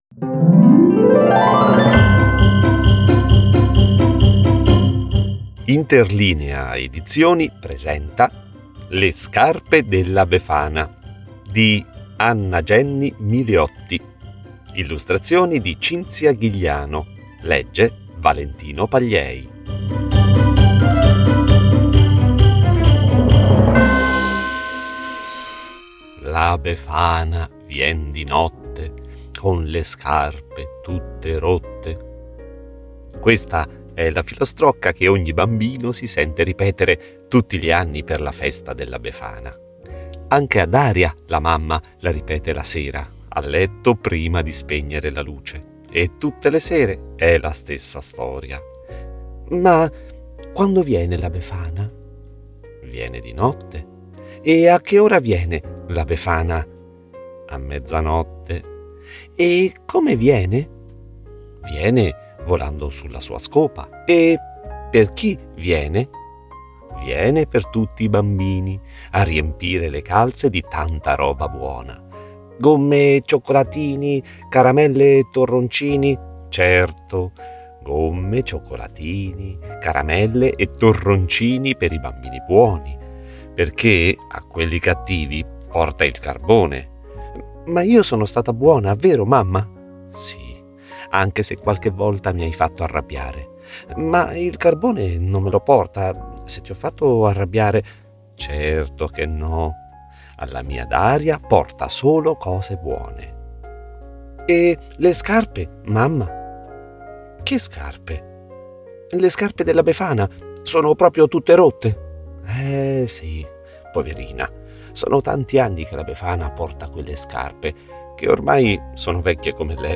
- Le scarpe della befana - Parte 1 - Audiolibro con tappeto sonoro
befana-(con musica)-puntata-1.mp3